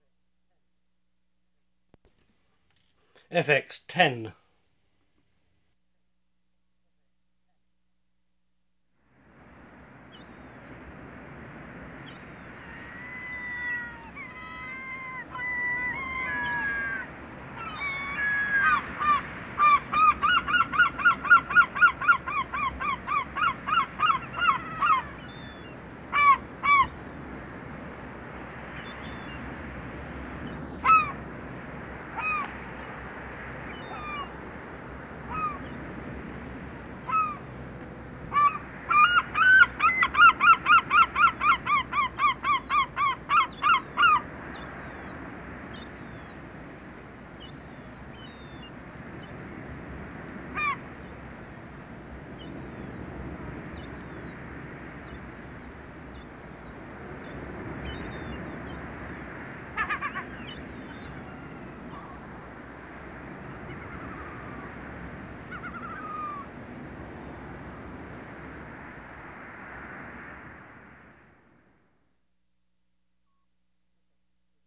SeaBirdsSeagulls.wav